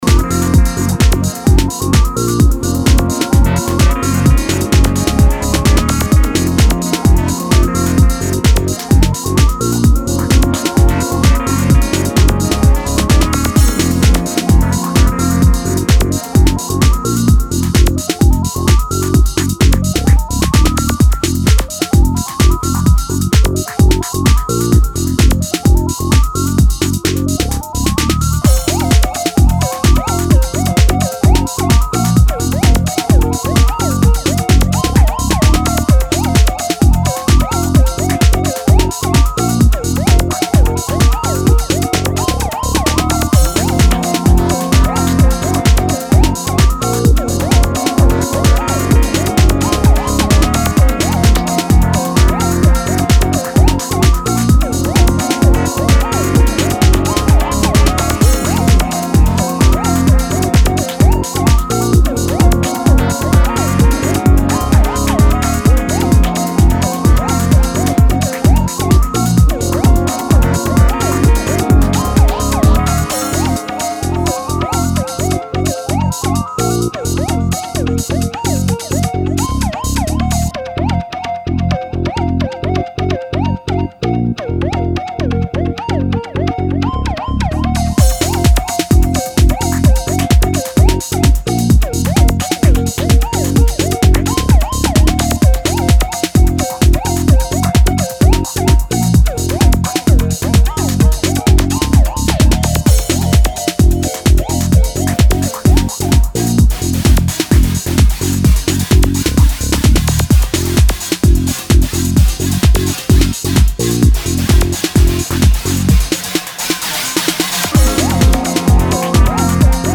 an entrancing track
characterized by its groovy and punchy nature.